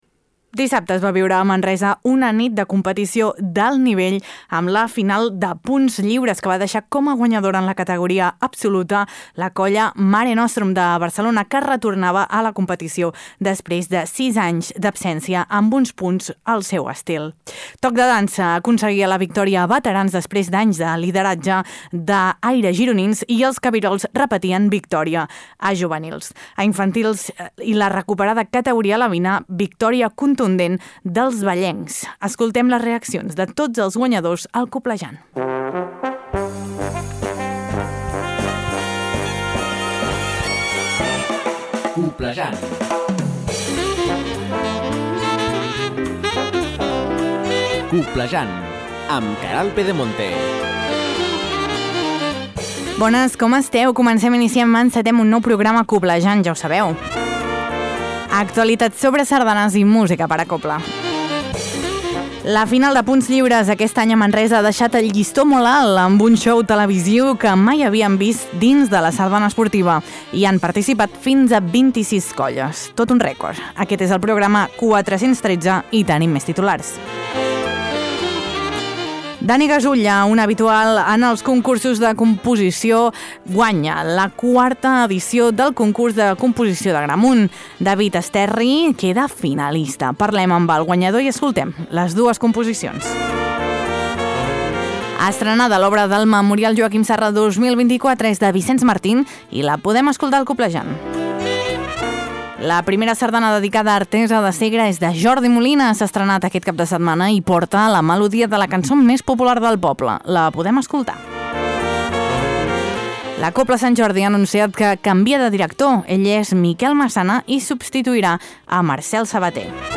Parlem amb el guanyador i escoltem les dues composicions!